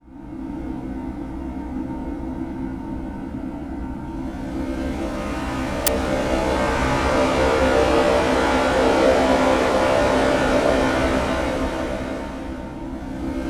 92.1CHNSAW.wav